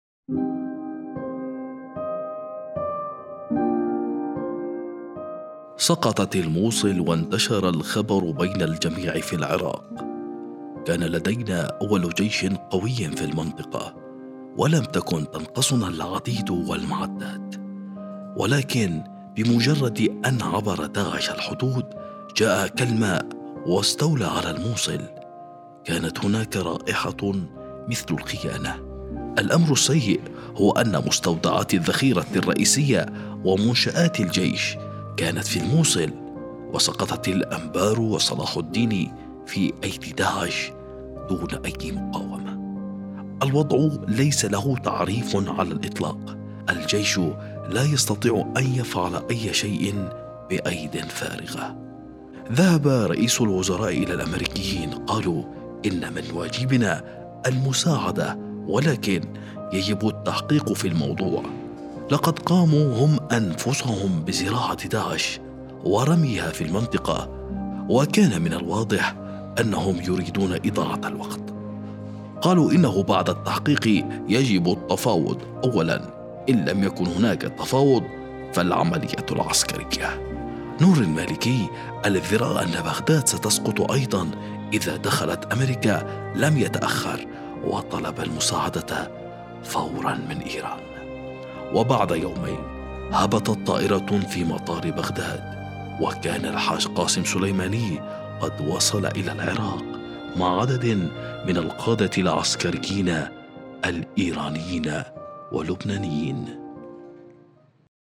الراوي: